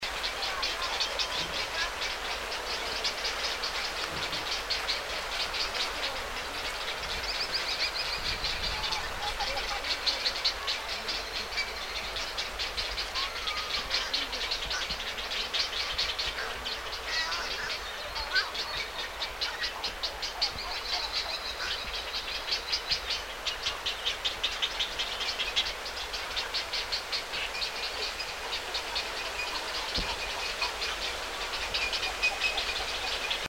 Kapverden ruskohaikara / Cap Verde Purple Heron (Ardea (purpurea) bournei)
Äänitetty ja kuvattu 15.1.2005 ainoassa tunnetussa pesimäkoloniassa, Santiagon saarella, Kapverden saarilla. / Recorded and photographed 15 January 2005 in the only known breeding colony, Santiago, Cap Verde Islands.
Yhdyskunnan ääniä / noise from the colony (784 kB)